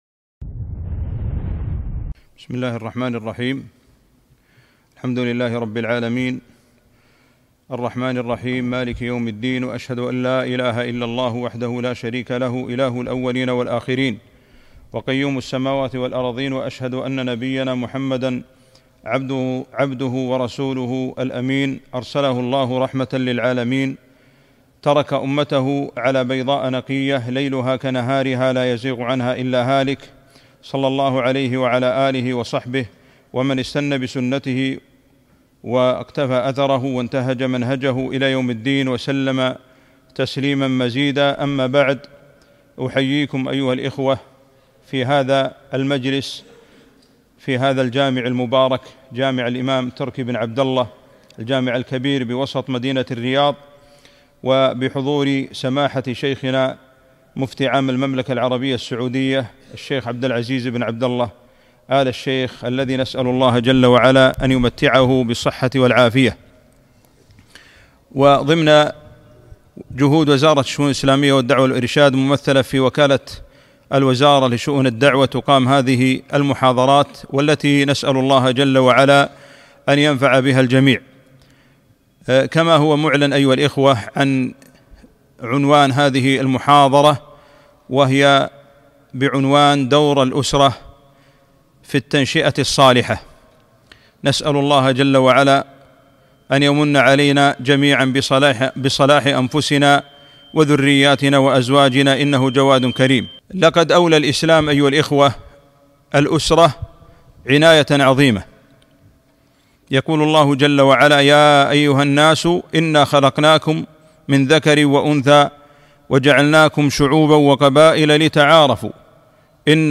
محاضرة - دور الأسرة في التنشئة الصالحة